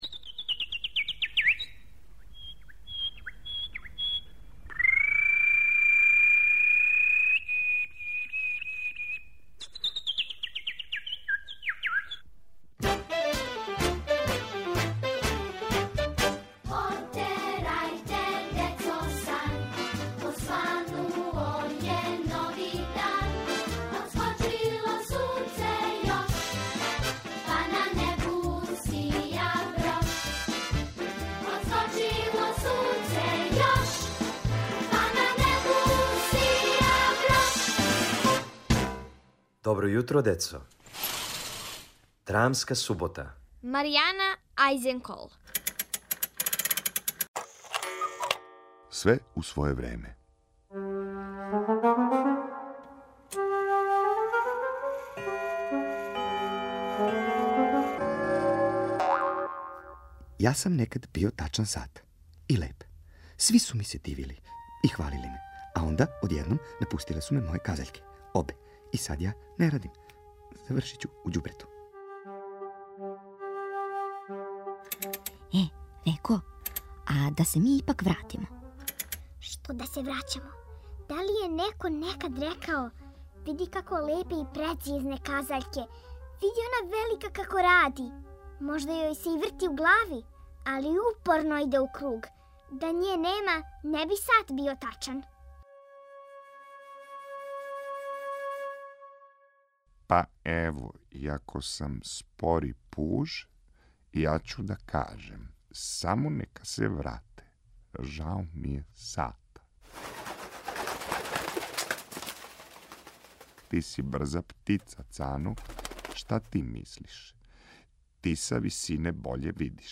Слушате други део мале радио драме "Све у своје време". У њој, казаљке су одлучиле да напусте сат.